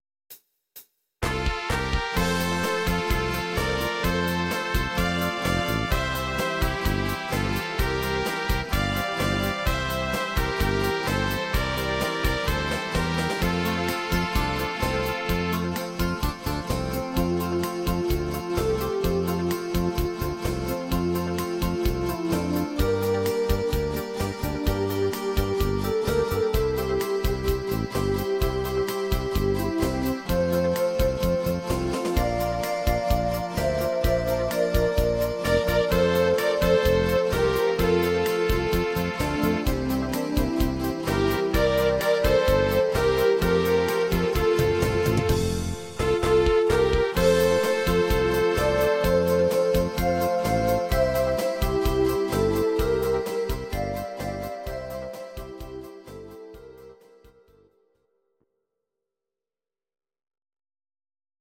instr. Zither